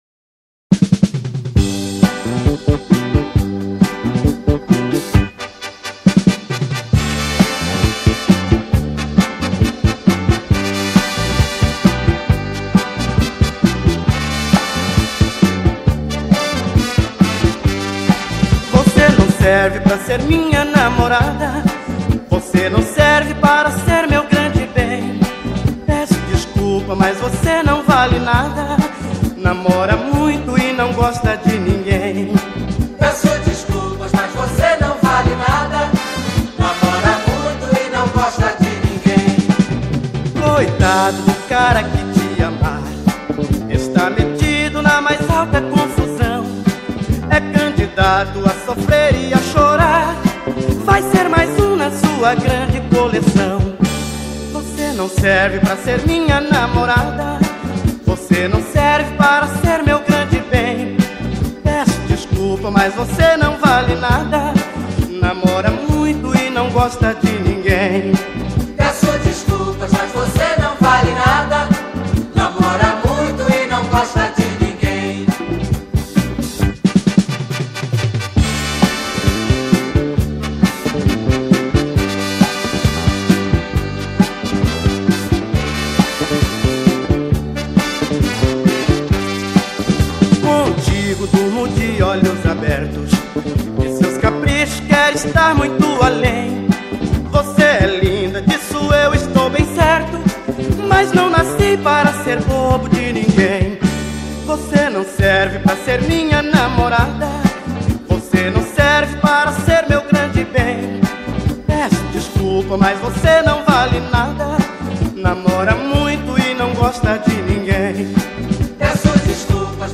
A PAUTA DE DOMINGO DO BAÚ ESTÁ RECHEADA DE BREGA